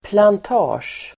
Ladda ner uttalet
plantage substantiv, plantation Uttal: [plant'a:sj]